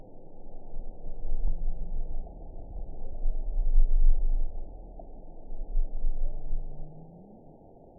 target species NRW